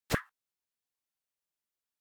snd_explo3.ogg